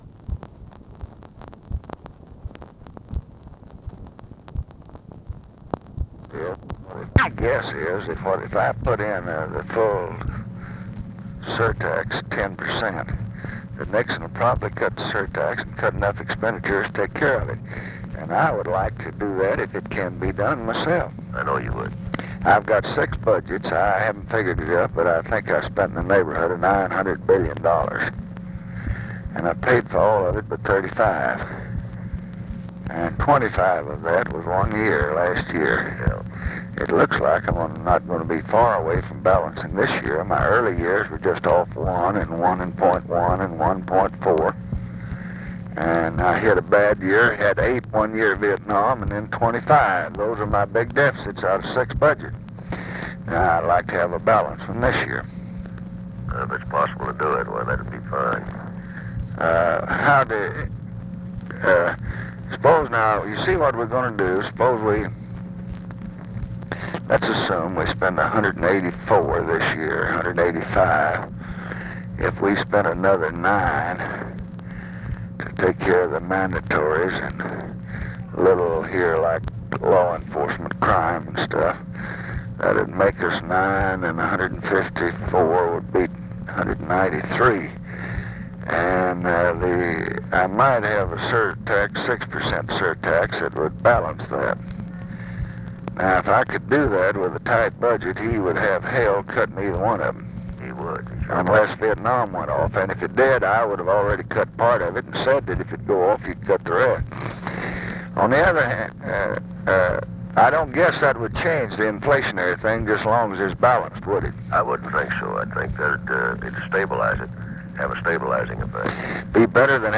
Conversation with WILBUR MILLS and OFFICE CONVERSATION, December 16, 1968
Secret White House Tapes